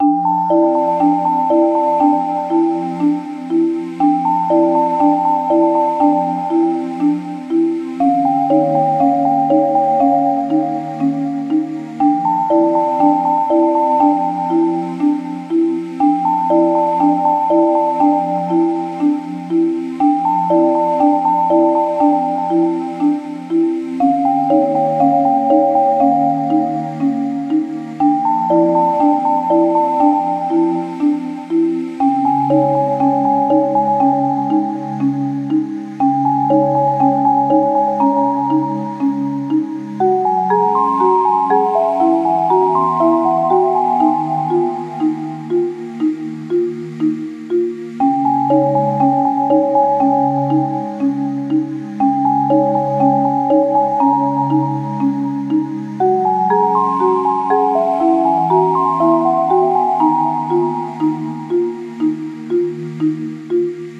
ゆったりした楽曲
【イメージ】しずかなダンジョン など